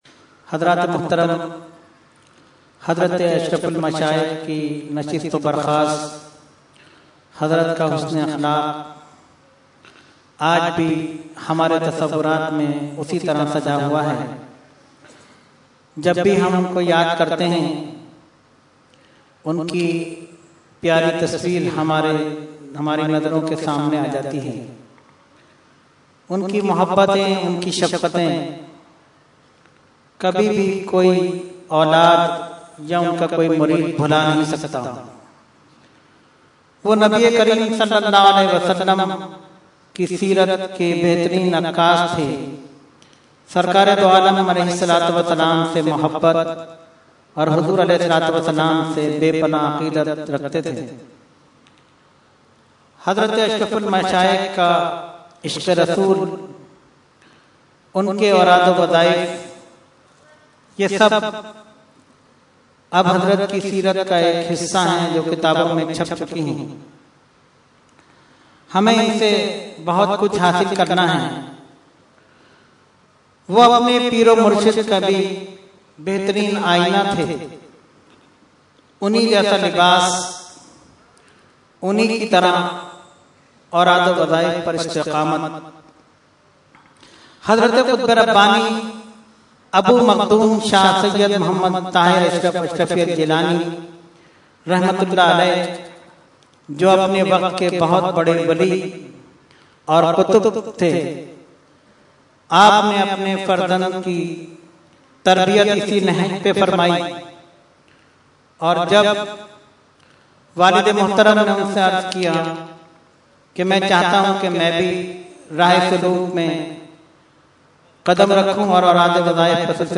Category : Speech | Language : UrduEvent : Urs Ashraful Mashaikh 2014